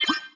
sci-fi_beep_computer_ui_05.wav